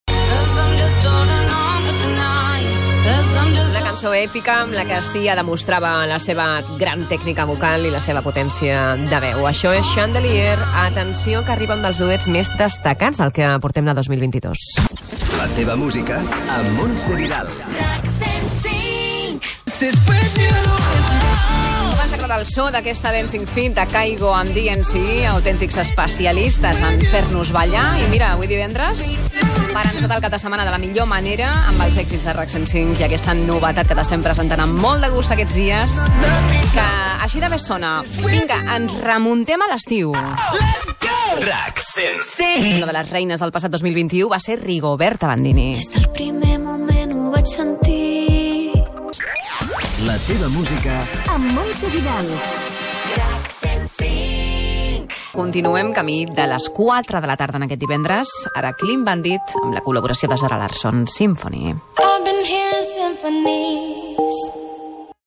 Indicatiu de la locutora i de l'emissora i tema musical
Musical
FM